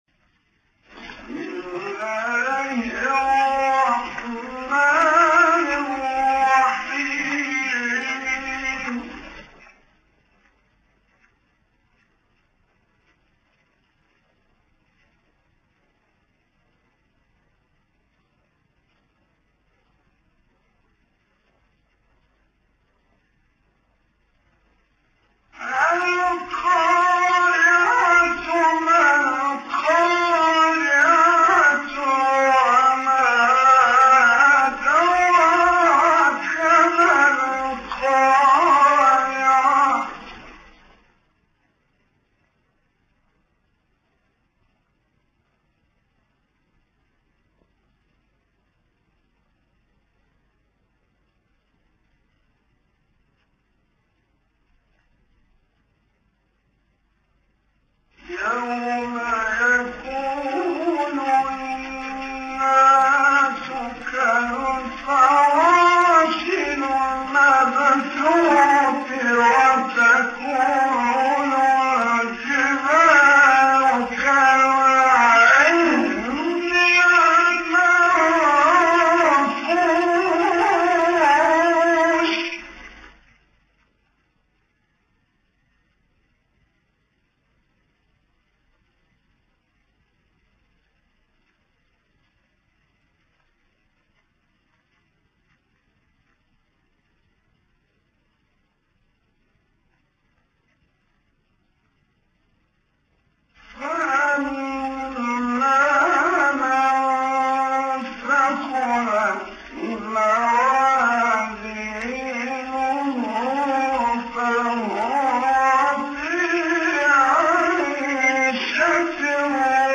سوره : قارعه آیه: 1-7 استاد : محمد رفعت مقام : حجاز قبلی بعدی